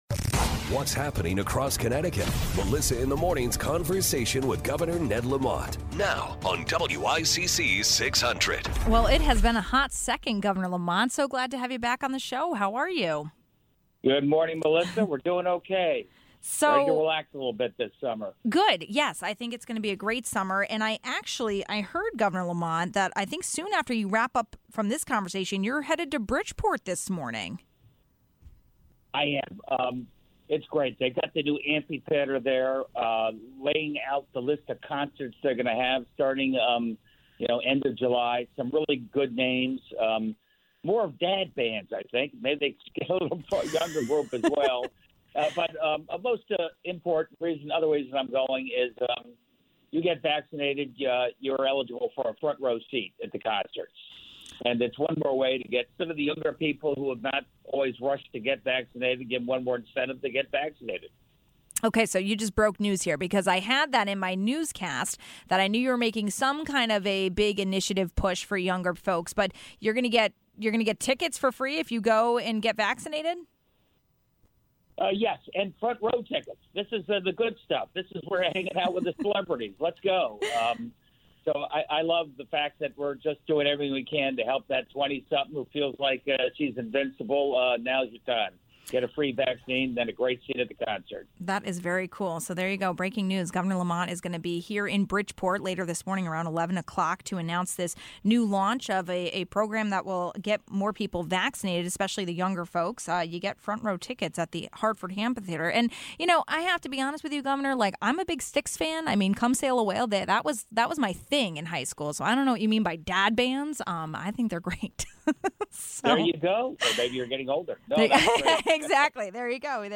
1. After a three-week break, Governor Lamont was back on the show. Following the end of the legislative session, he talked about the budget and marijuana. But also addressed protests around masks and children as well as the shift in major corporations moving to Connecticut.